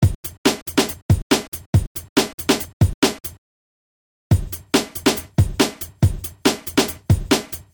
Here’s an example of two beats, one with all the sounds chopped straight off a loop and the second with some of the tails recreated.